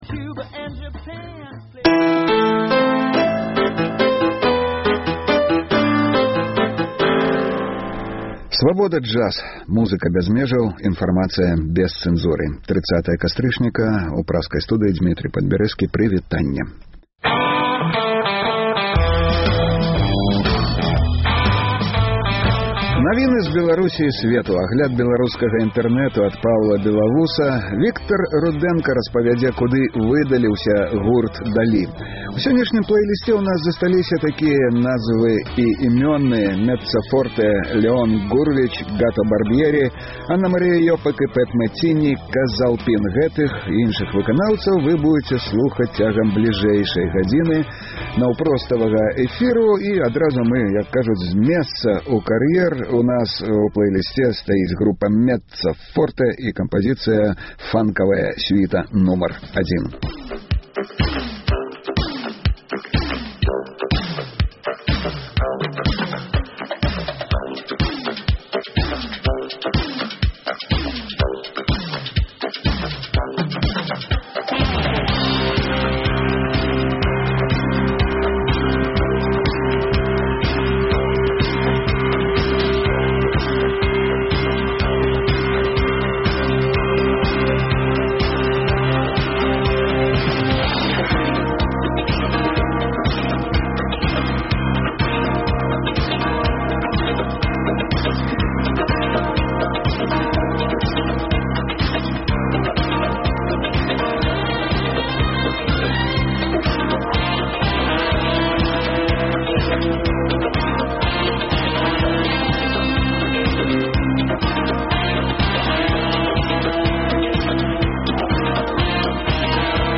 Слухайце ад 12:00 да 14:00 жывы эфір Свабоды!